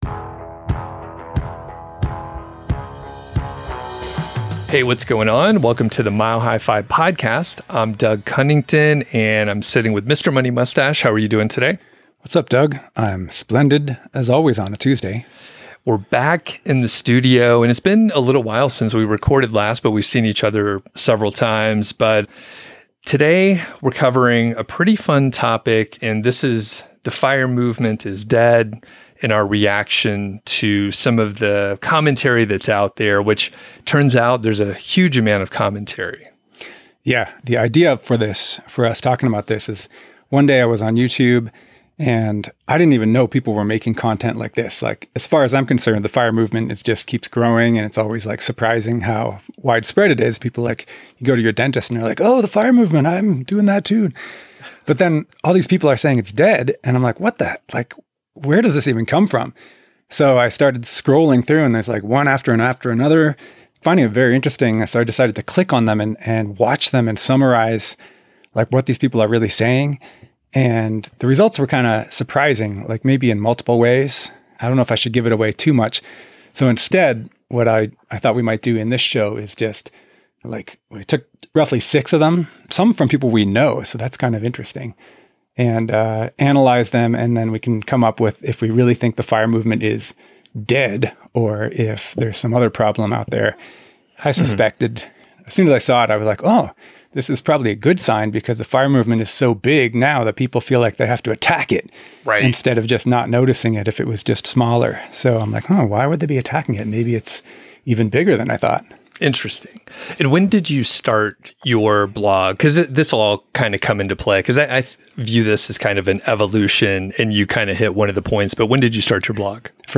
chats with Pete Adeney, Mr. Money Mustache, about whether the FIRE Movement is dead, in response to a variety of online commentary claiming this statement as fact.